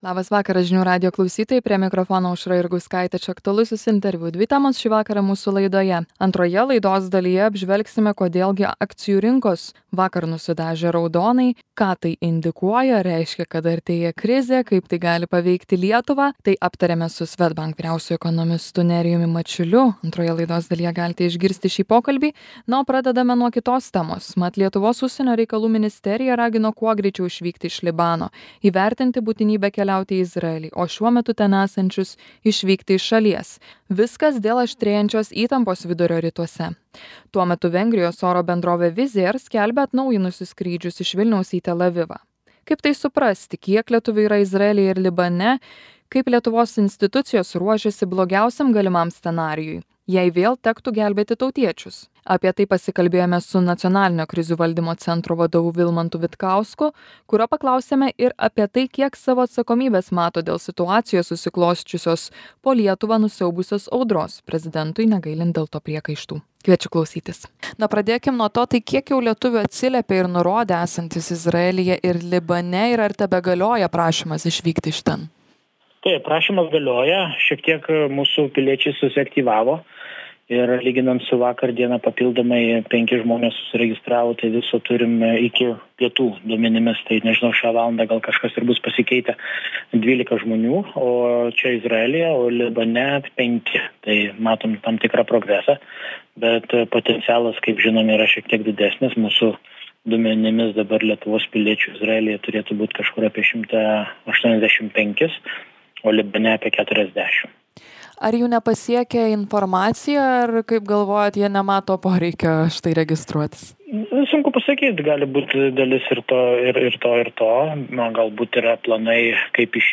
Kalbame su Nacionalinio krizių valdymo centro vadovu Vilmantu Vitkausku – jo klausiame ir apie tai, kiek savo atsakomybės mato dėl situacijos, susiklosčiusios po Lietuvą nusiaubusios audros, prezidentui dėl to negailint priekaištų.